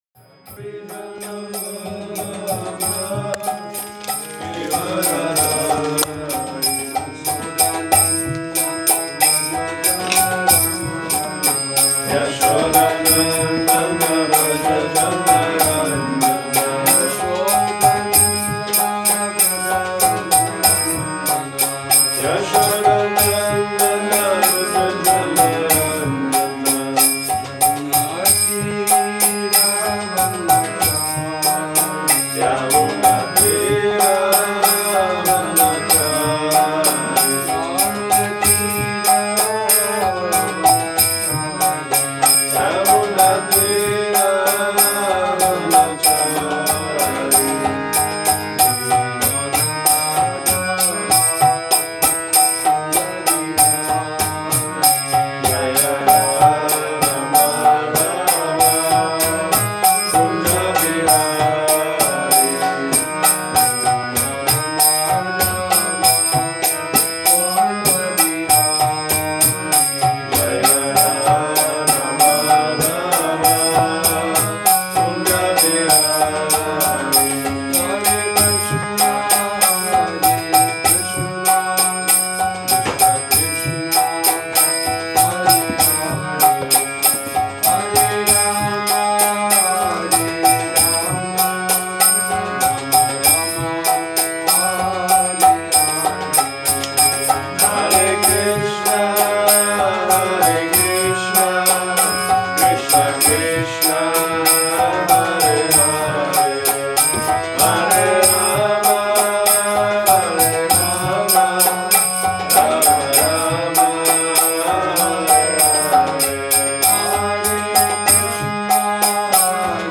Přednáška SB-11.13.1 – Šrí Šrí Nitái Navadvípačandra mandir